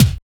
TEK THUMP K.wav